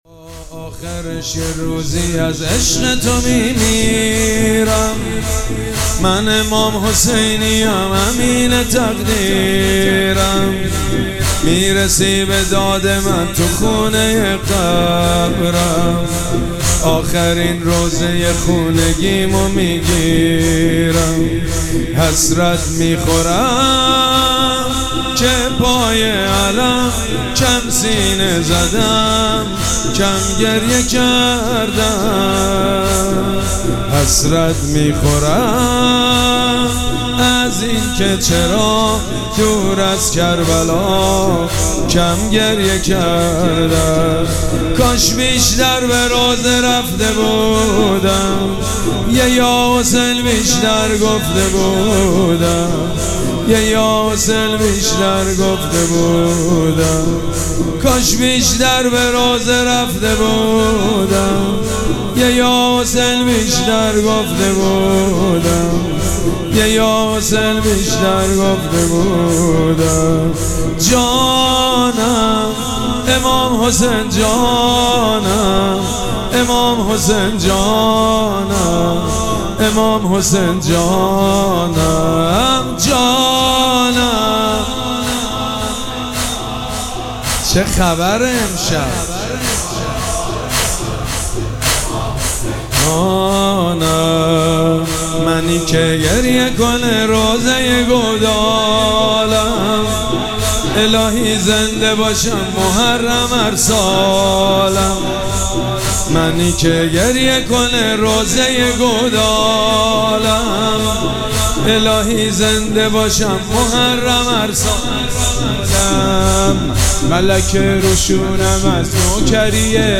شب چهارم مراسم عزاداری اربعین حسینی ۱۴۴۷
مراسم عزاداری اربعین حسینی